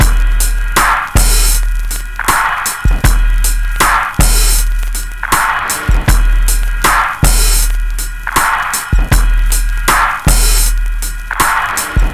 Squeeky Rim 01.WAV